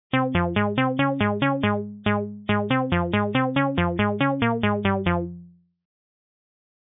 This sample is from the "chorse" and it should be piano...